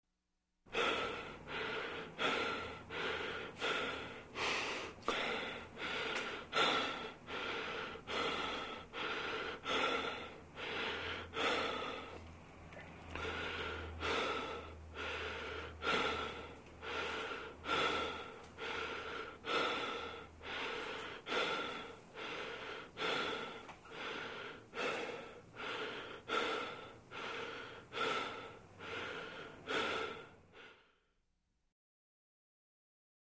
Звуки вздоха человека
Тяжелое дыхание